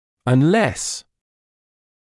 [ən’les][эн’лэс]если только не; пока не